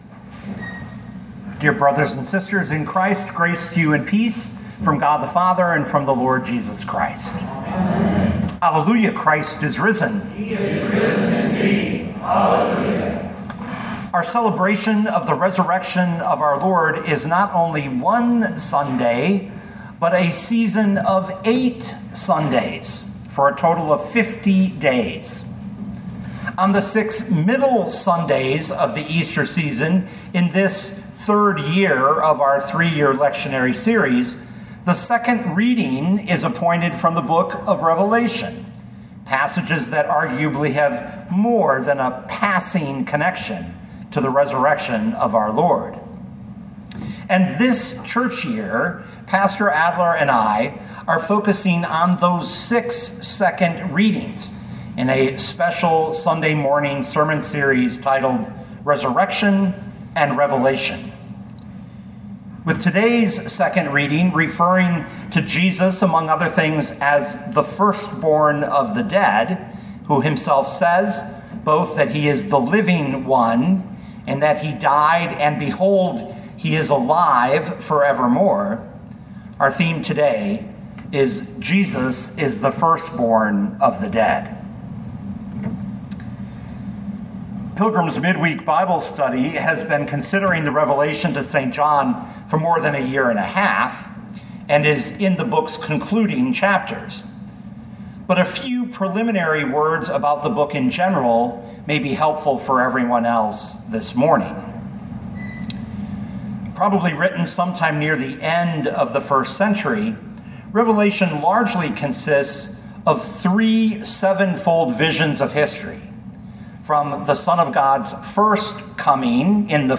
2025 Revelation 1:4-18 Listen to the sermon with the player below, or, download the audio.